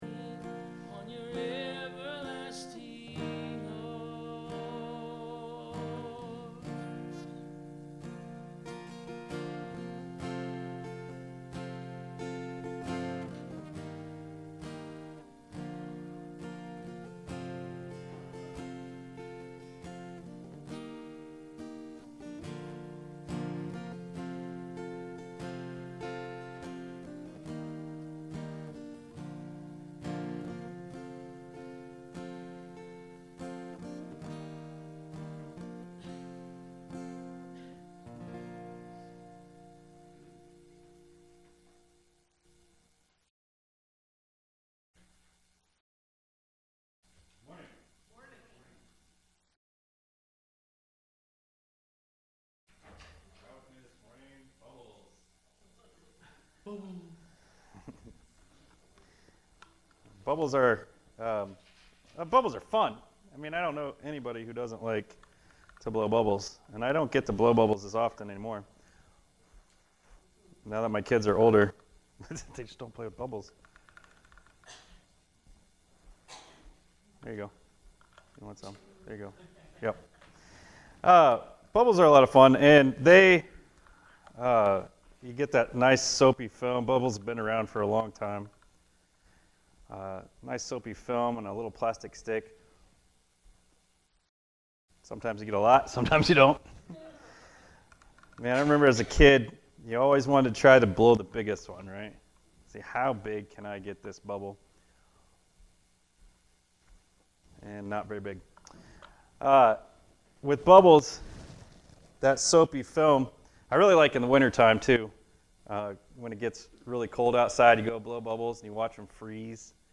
(Some sound issues this morning)